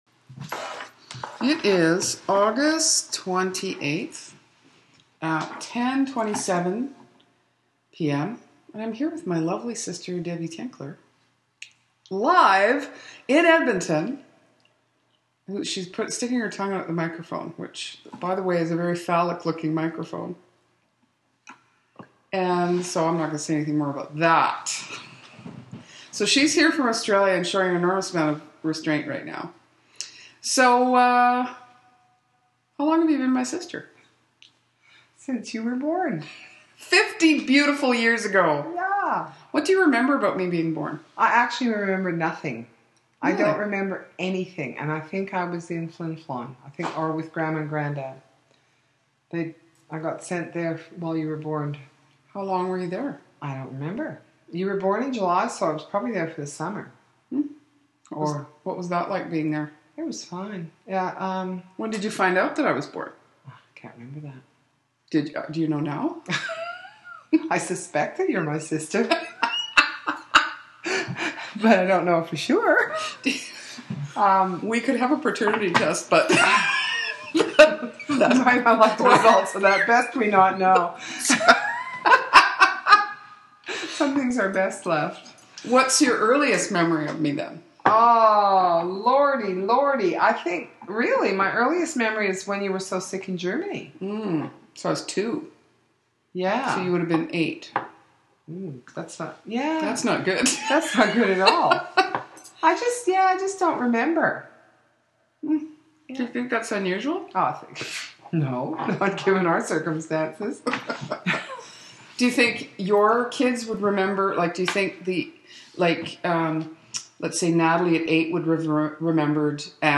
More importantly, we think we can sing.